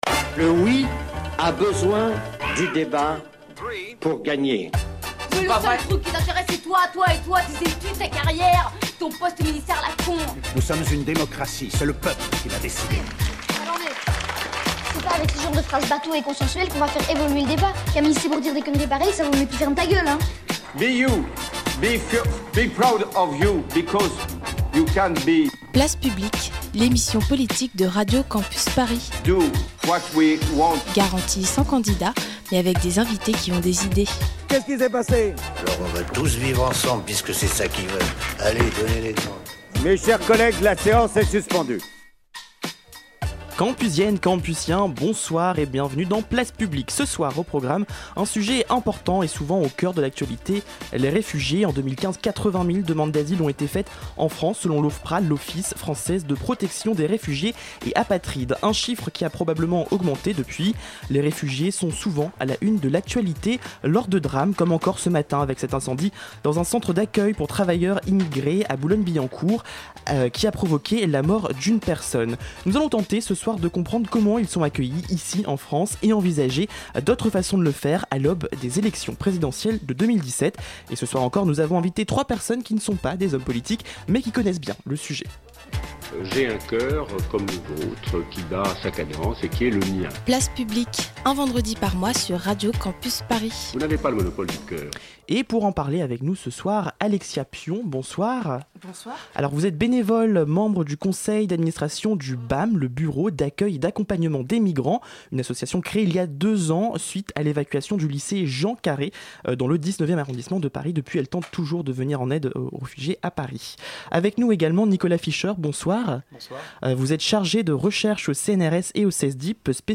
En tous cas dans Place Publique, l'émission politique de Radio Campus Paris, garantie sans langue de bois mais avec des invités qui ont des idées, on continue de réfléchir aux enjeux du monde de demain. Ce mois-ci on s'intéresse à l'accueil des réfugiés (ou migrants, comme vous voulez).